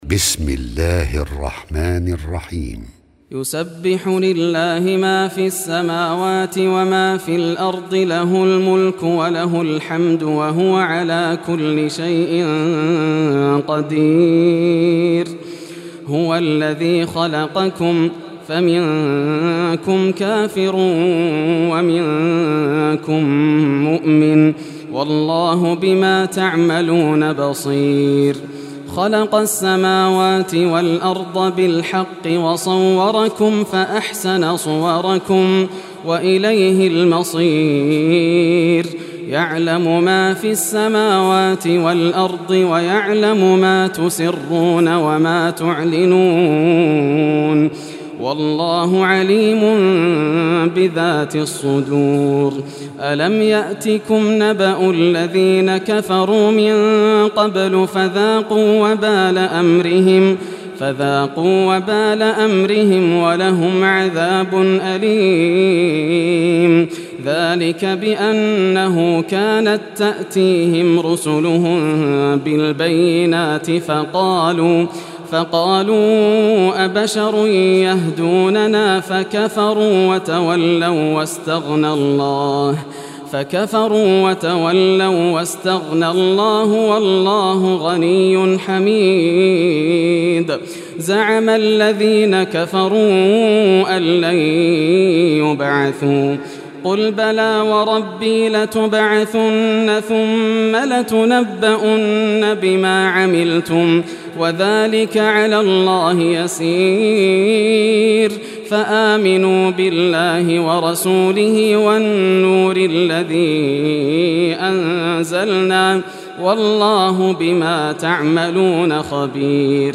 Surah At-Taghabun Recitation by Yasser al Dosari
Surah At-Taghabun, listen or play online mp3 tilawat / recitation in Arabic in the beautiful voice of Sheikh Yasser al Dosari.
64-surah-taghabun.mp3